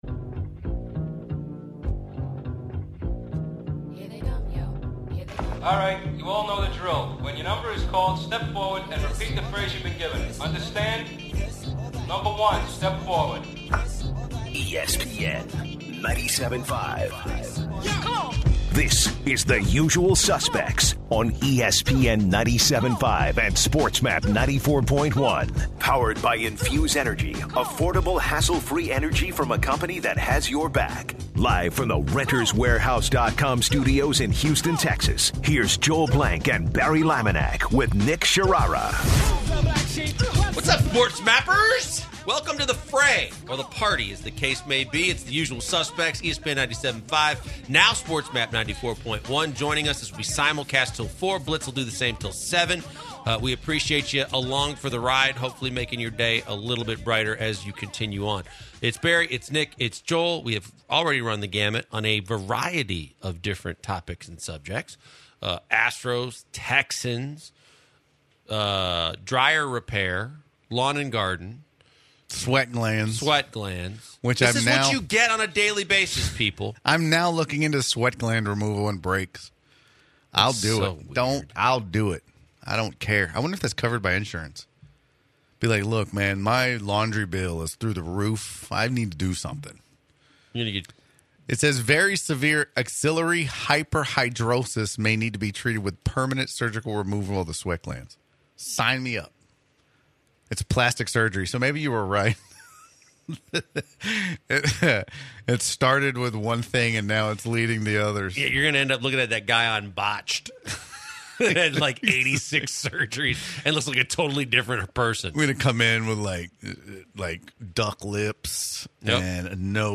The guys talk about the suspensions of 13 UNC football players for selling their school-issued NIKE shoes. They close out the show by taking calls from listeners about college and college athletes being compensated.